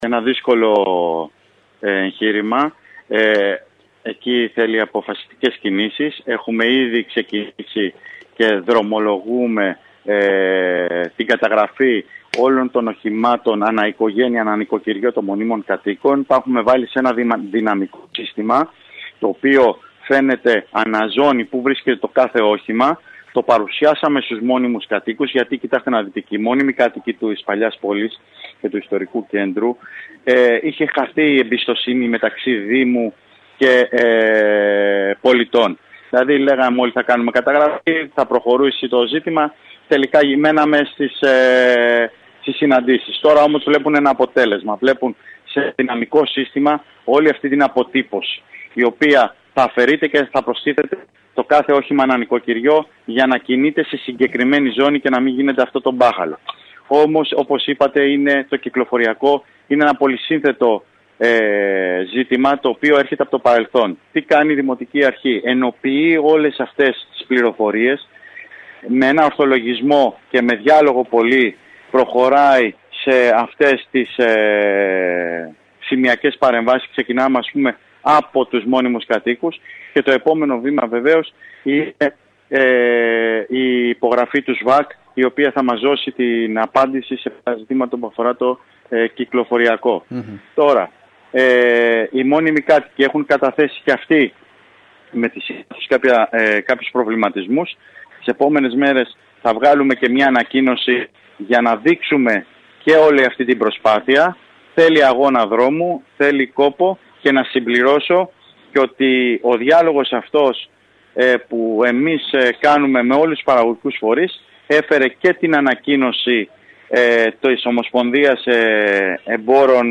Στόχος, όπως ανέφερε ο Αντιδήμαρχος Τεχνικών Υπηρεσιών, Νίκος Καλόγερος στην ΕΡΤ Κέρκυρας, είναι να βρεθεί η χρυσή τομή προκειμένου και η πρόσβαση των κατοίκων προς τις οικίες τους να καταστεί λειτουργική και ο όγκος των αυτοκινήτων να σταματήσει να επιβαρύνει το ιστορικό κέντρο της Κέρκυρας.